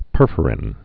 (pûrfər-ĭn)